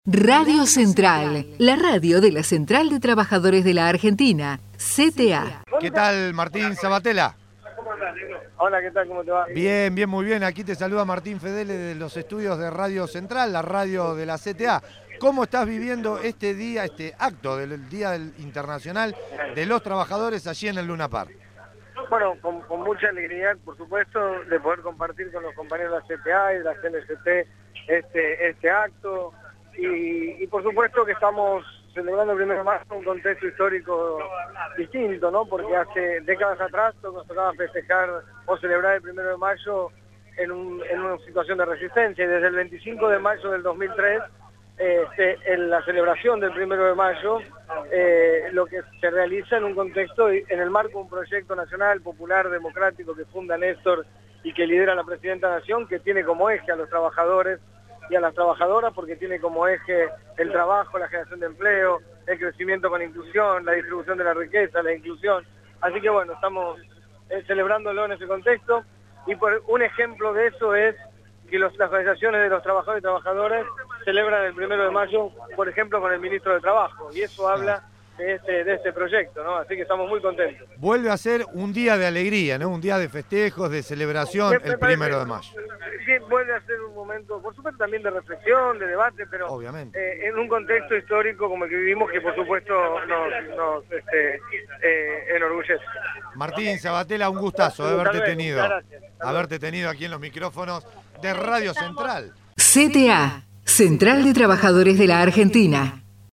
MARTIN SABBATELLA - ACTO 1º de MAYO - LUNA PARK